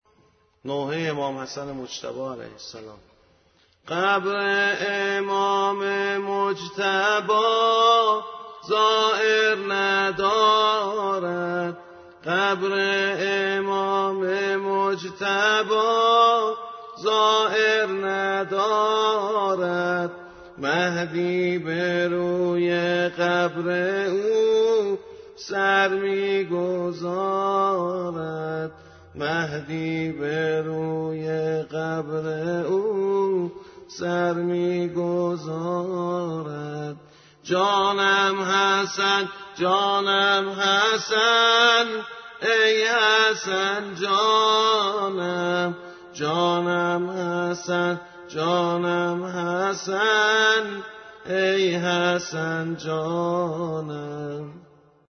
nohe.mp3